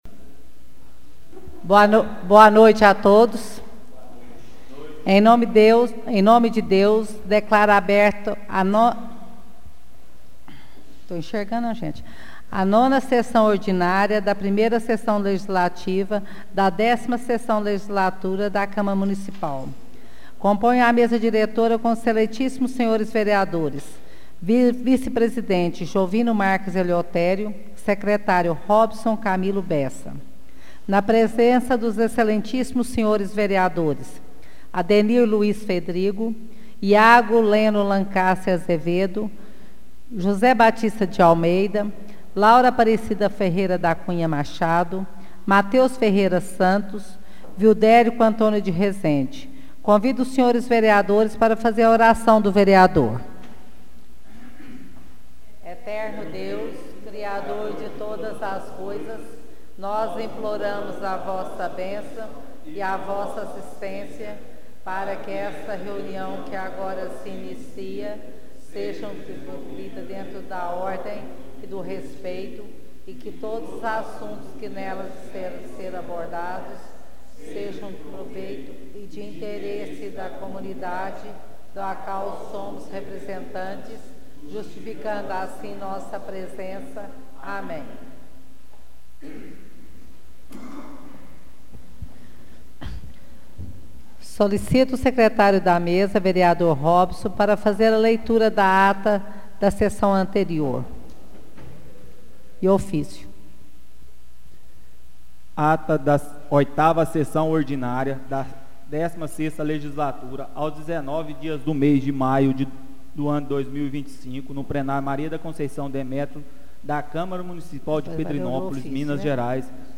Áudio da 9ª Sessão Ordinária de 2025